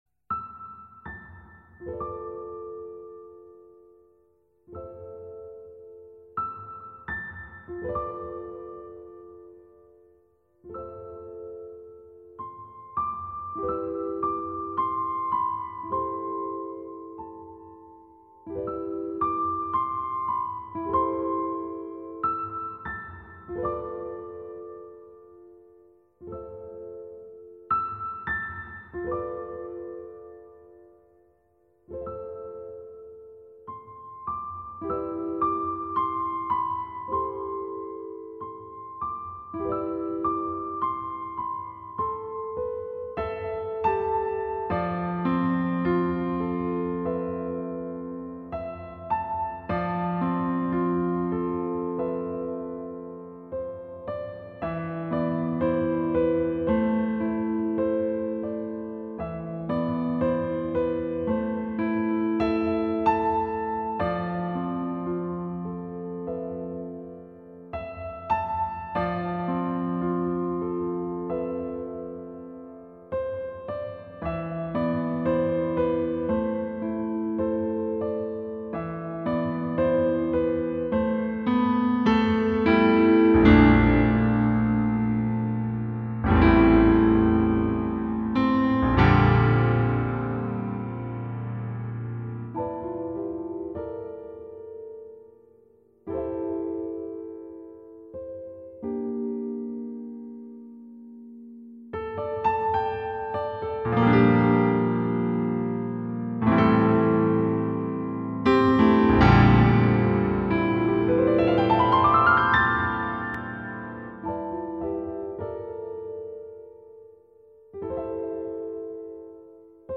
其配乐精湛若斯，音符跌宕，随着历史叙述的起伏不断变换着节奏，低沉的打击乐仿佛敲响了永乐朝的大钟